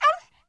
client / bin / pack / Sound / sound / monster / stray_dog / damage_1.wav
damage_1.wav